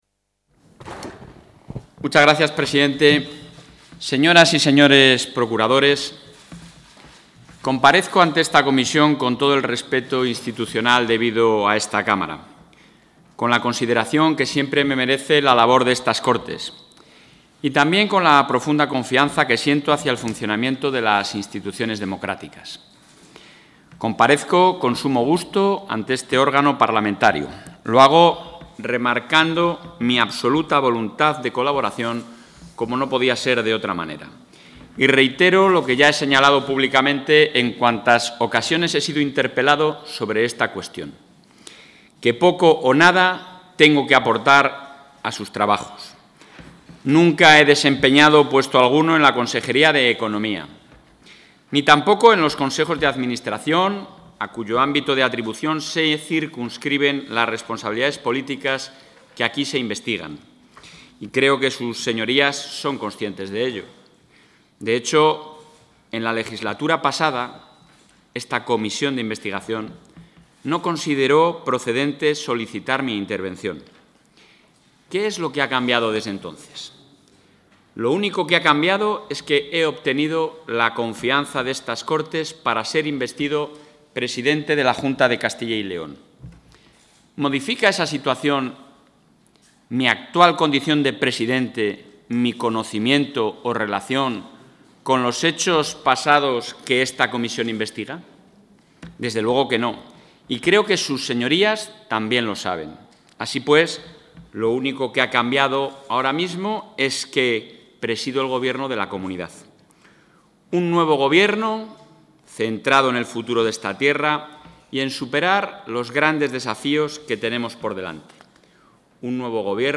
En su comparecencia en la comisión de investigación las Cortes de Castilla y León, Alfonso Fernández Mañueco ha...
Intervención del presidente.